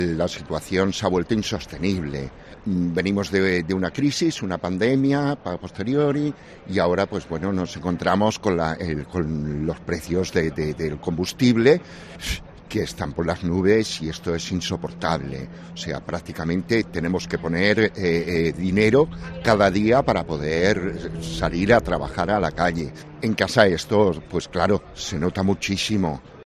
Taxista que participa en la marcha lenta de Barcelona